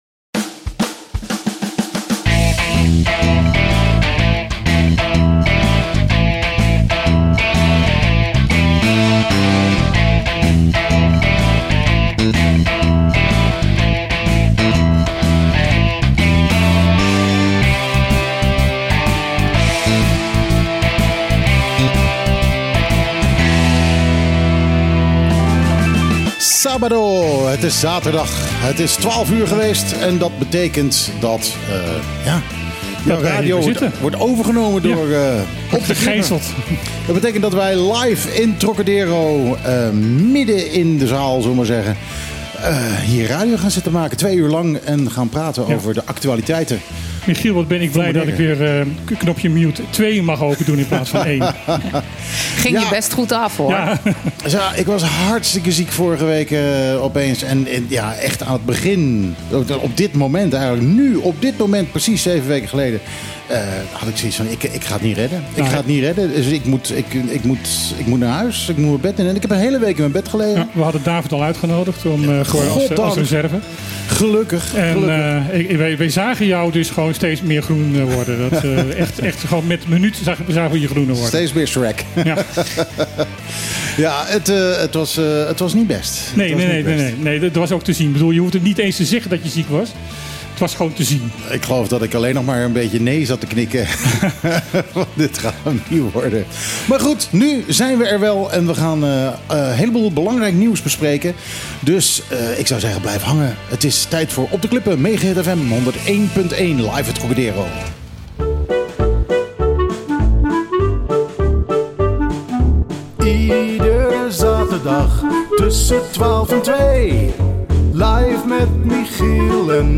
Het werd een levendig gesprek. Daarna behandelde het team van Op de Klippen nog een flink aantal andere onderdelen van het nieuwsaanbod van de afgelopen week.